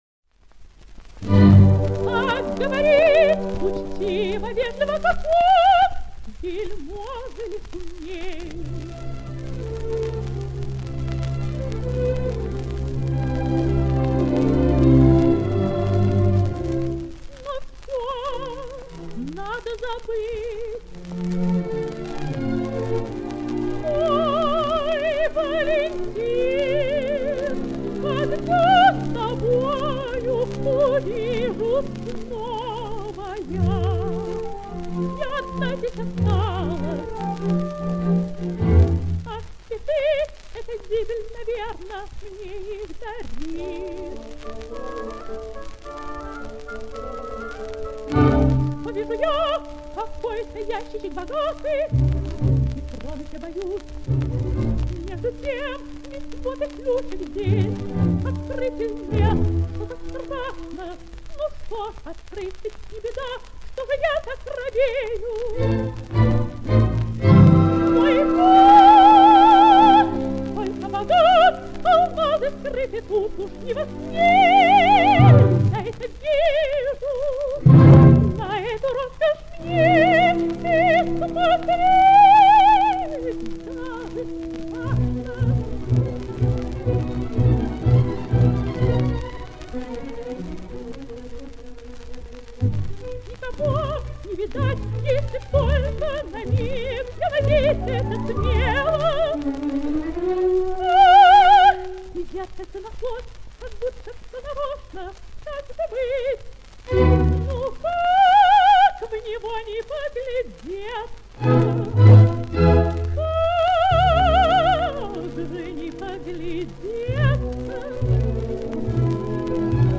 Опера «Фауст». Ария Маргариты. Оркестр Государственного Академического Большого театра СССР.
Исполняет Н. Д. Шпиллер.